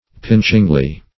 pinchingly - definition of pinchingly - synonyms, pronunciation, spelling from Free Dictionary Search Result for " pinchingly" : The Collaborative International Dictionary of English v.0.48: Pinchingly \Pinch"ing*ly\, adv.